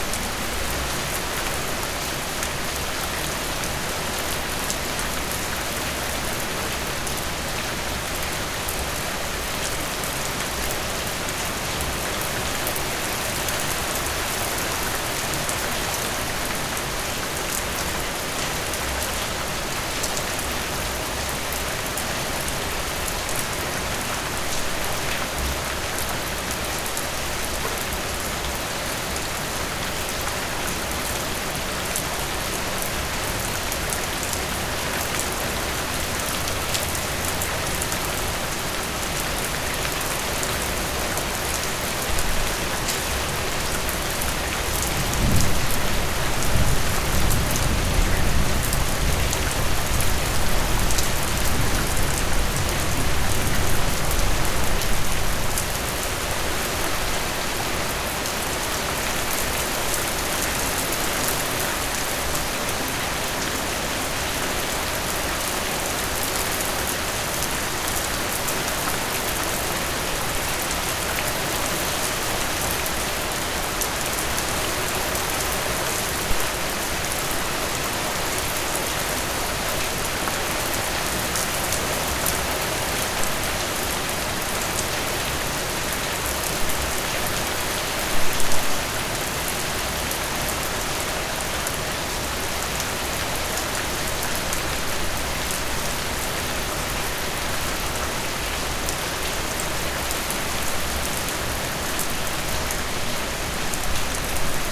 rain.ogg